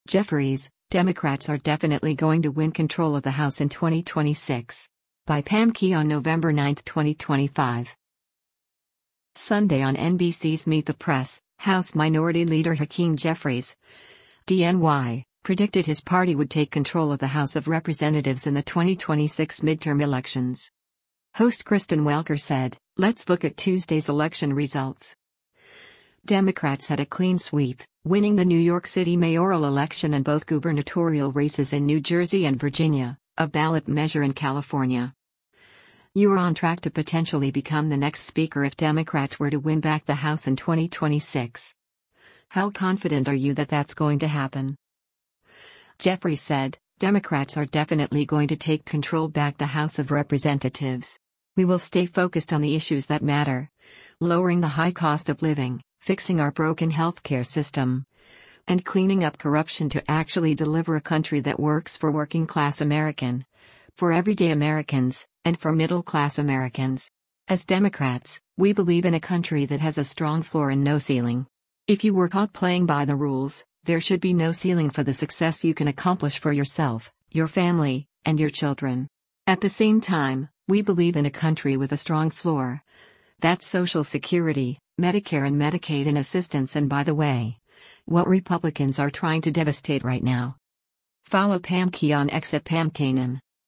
Sunday on NBC’s “Meet the Press,” House Minority Leader Hakeem Jeffries (D-NY) predicted his party would take control of the House of Representatives in the 2026 midterm elections.